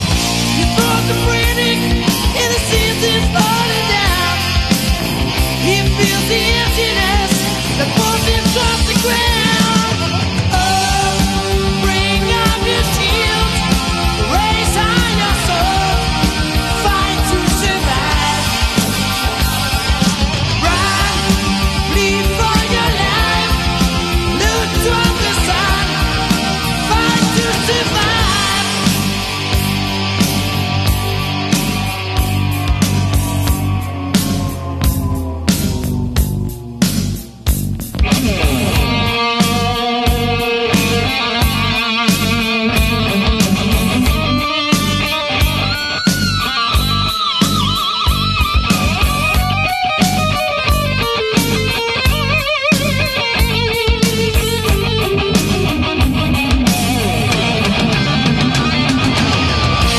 glam metal band